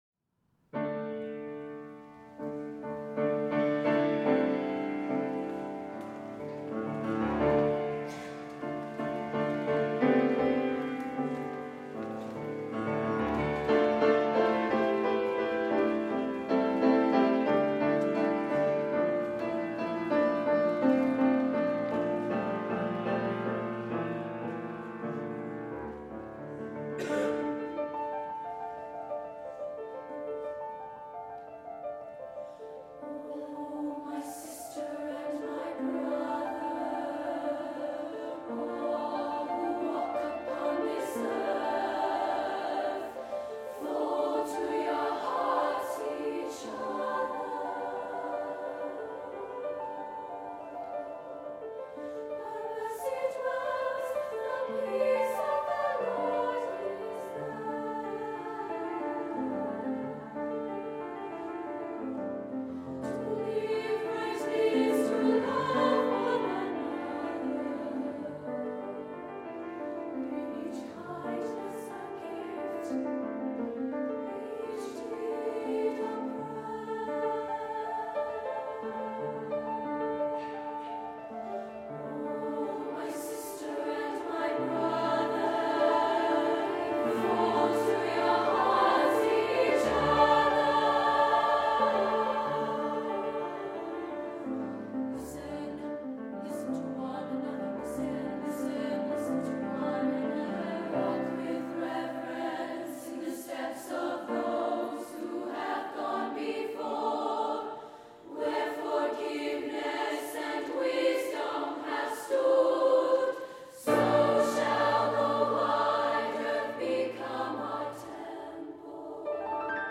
SSA version